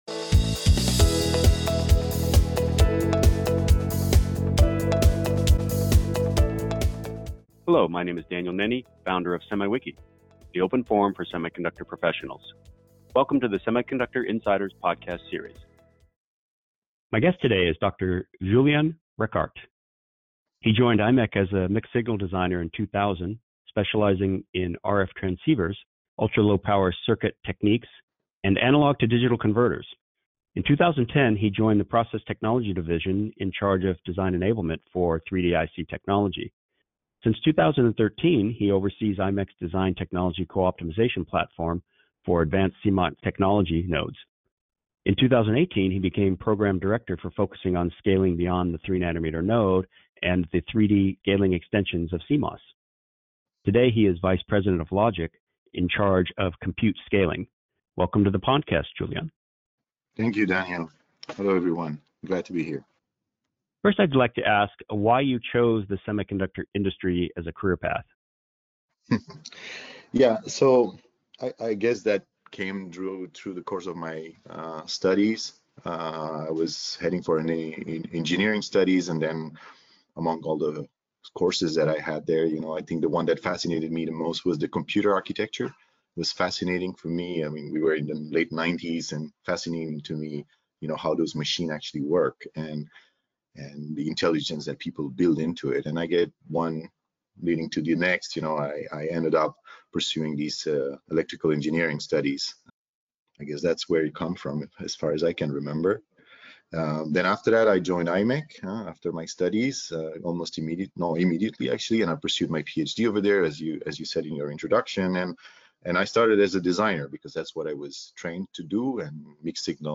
In this broad and varied discussion